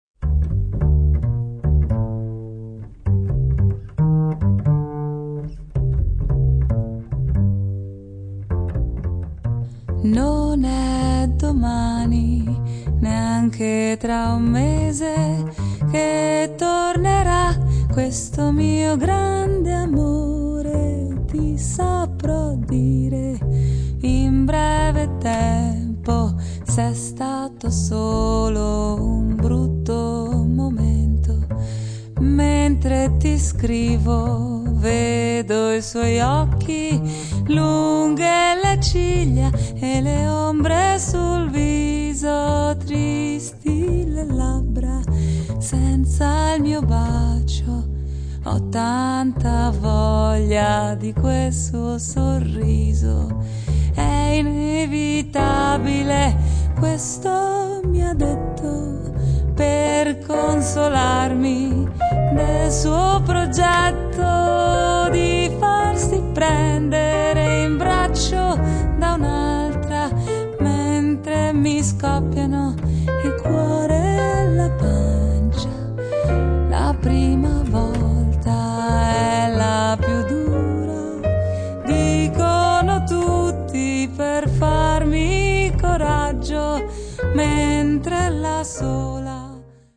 La bella e morbida voce